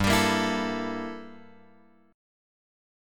Gm6add9 chord {3 x 5 3 5 5} chord